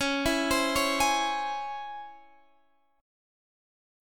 Listen to DbmM7 strummed